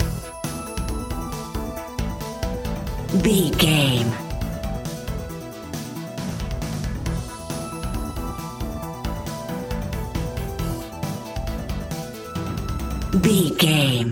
Dorian
Fast
driving
epic
energetic
bass guitar
synthesiser
percussion
electric piano